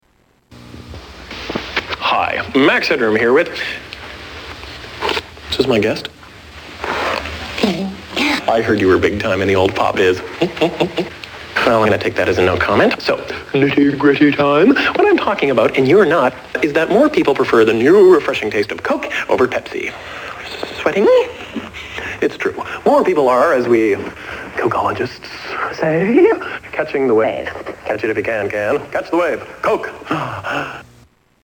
Max Headroom Coke Commercial
Category: Television   Right: Personal